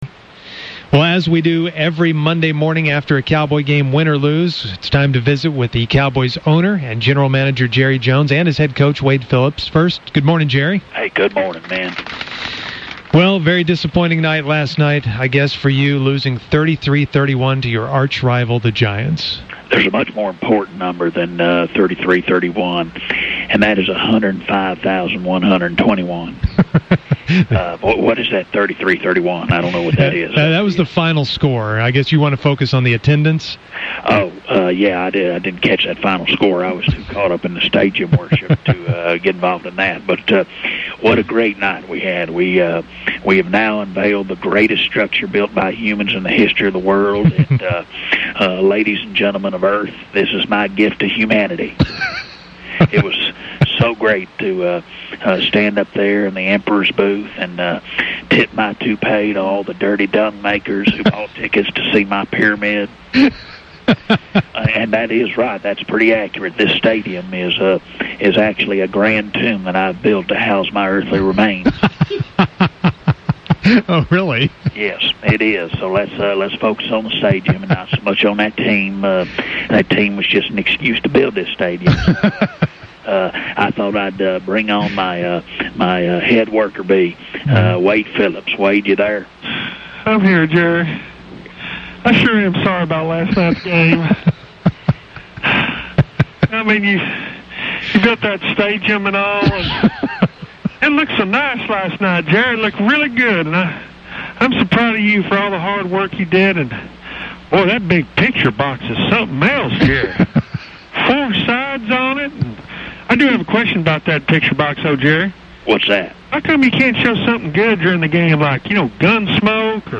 Fake Wade was clearly upset, he had eaten something bad from the concession line. Then Jerry goes off on Wade when he thinks he’s off air.